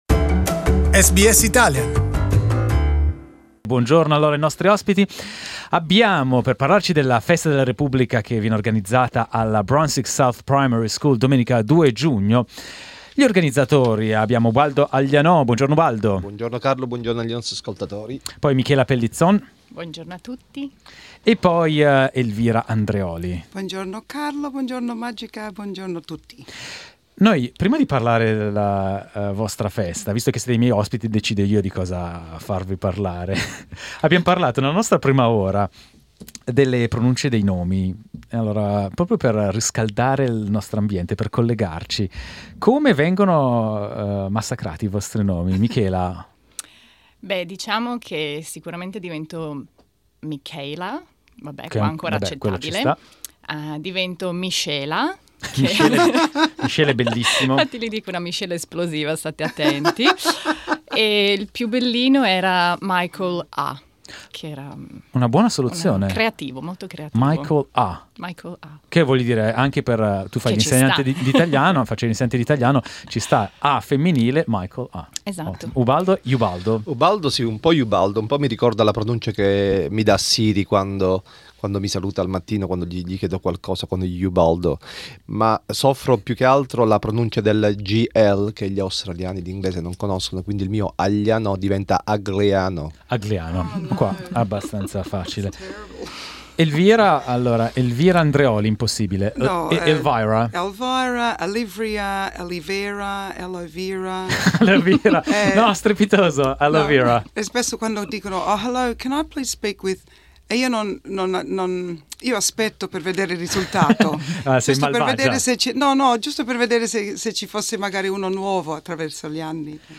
in our studios